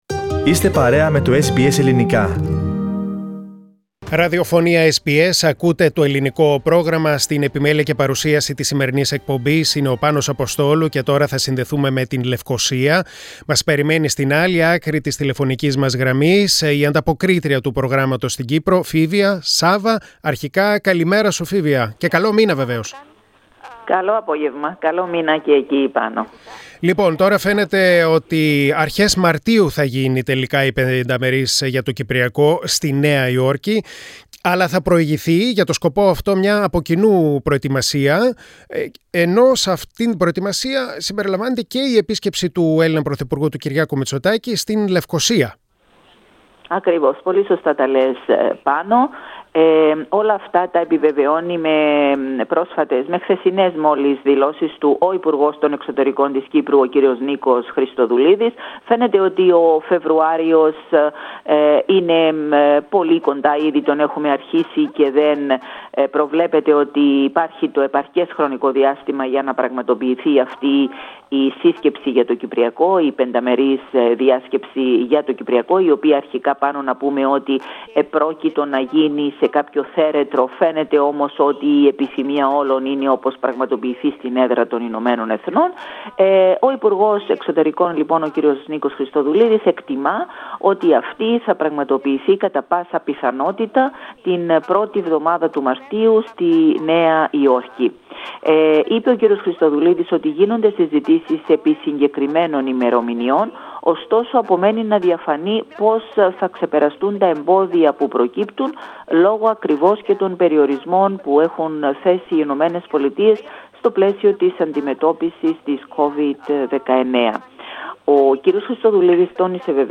Πατήστε play στην κεντρική φωτογραφία για να ακούσετε το podcast με ολόκληρη την ανταπόκριση